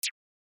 menuhit.mp3